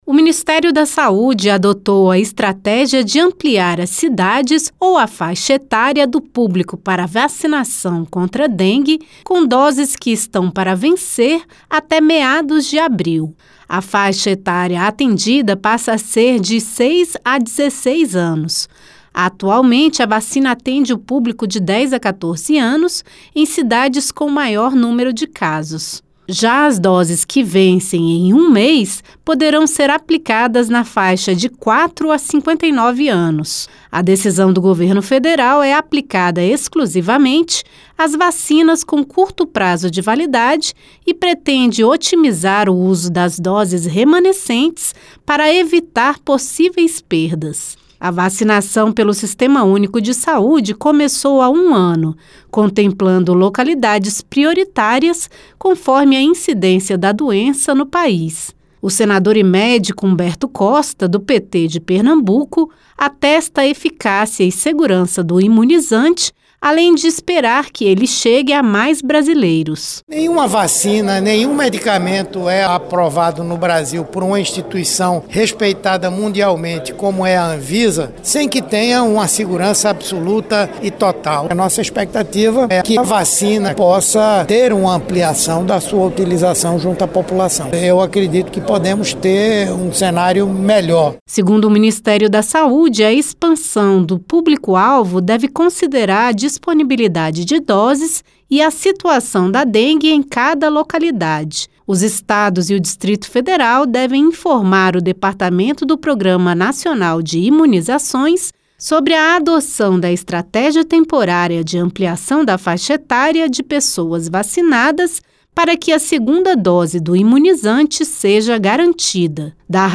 Vacinas que têm validade até meados de abril serão destinadas a ampliar o público-alvo da imunização no país, hoje estabelecido entre 10 e 14 anos e em locais com maior incidência de dengue. A medida adotada pelo Ministério da Saúde visa evitar o desperdício de imunizantes e atingir mais brasileiros. O senador Humberto Costa (PT-PE), que é médico, ressalta a qualidade da vacina.